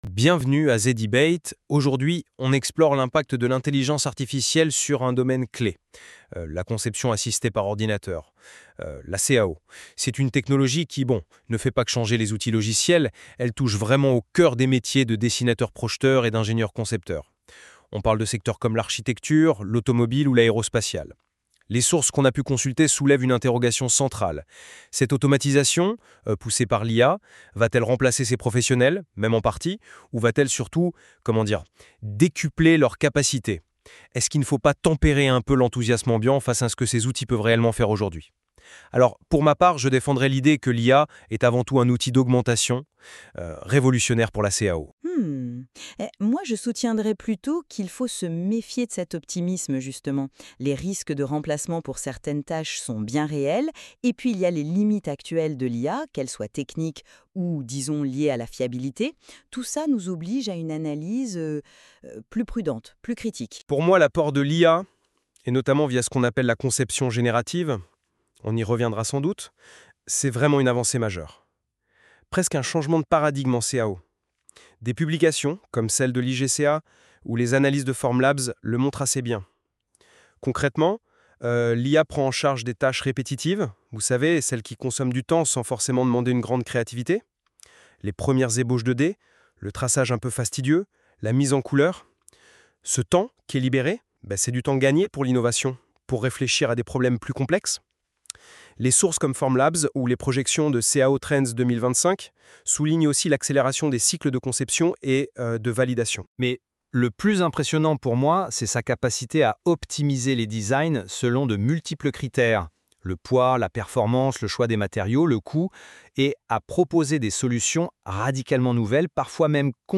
[!tip] écoutez un débat sur ce thème /uploads/default/original/2X/a/af3cf7a2e3cb5123fa89075d02563c16b47e35bf.mp3